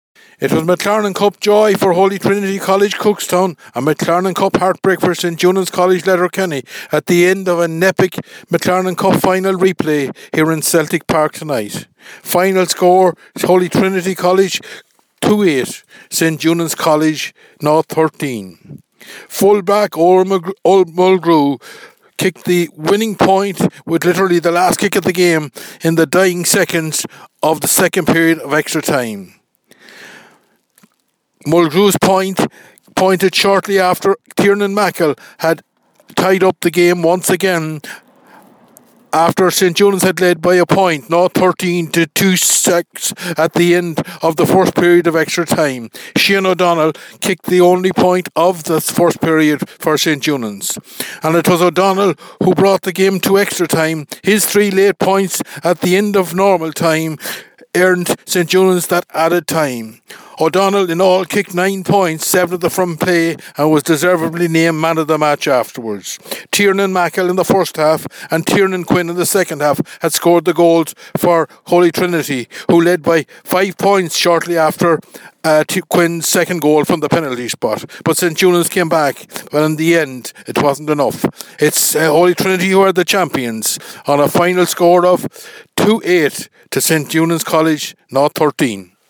reports for Highland Radio Sport…